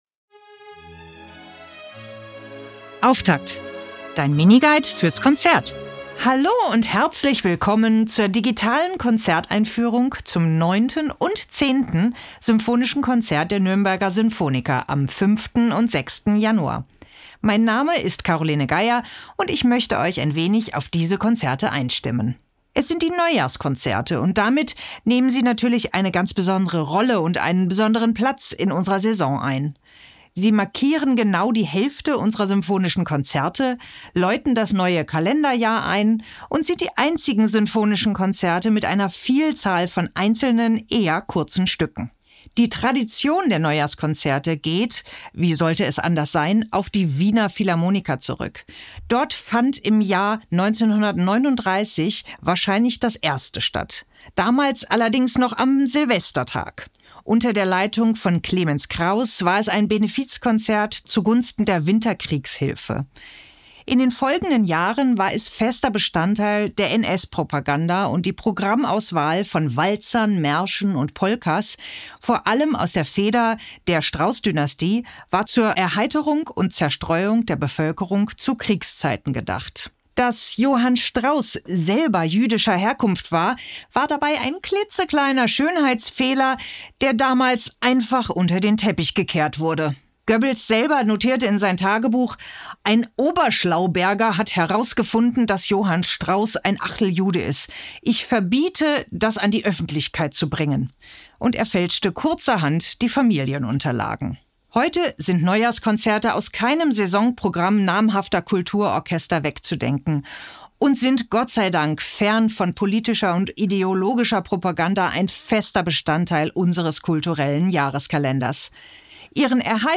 Musik Jingle: Serenade für Streicher, 2. Satz – Tempo di Valse‘
Aufnahme von 1949, Dirigent: Alexander Gauk, Violoncello: